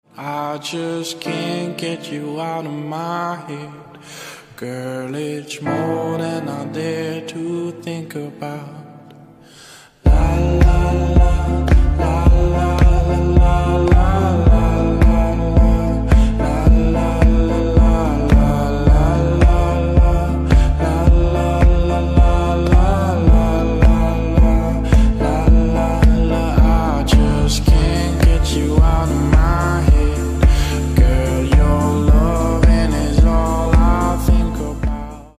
• Качество: 320, Stereo
мужской голос
красивые
deep house
спокойные